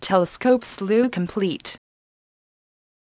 Your choice of natural sounding voice keeps you informed of what your telescope is doing, indicating events such as, "telescope slew complete."